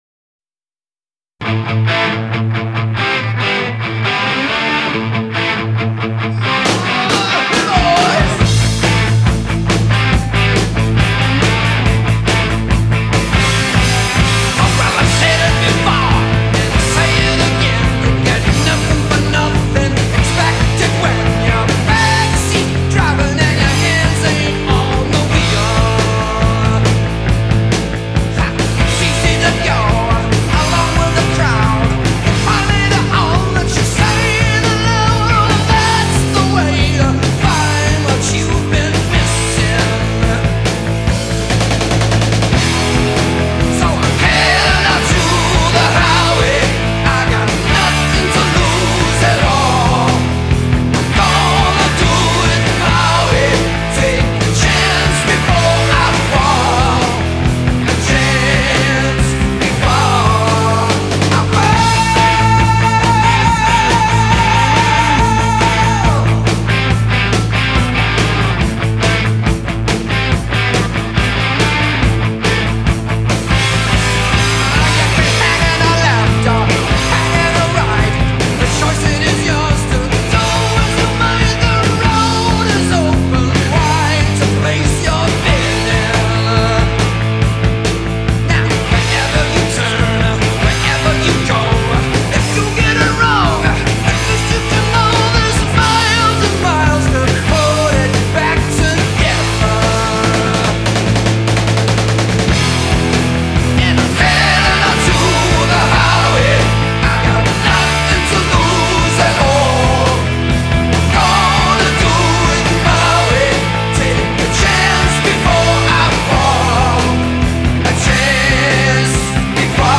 heavy metal هوی متال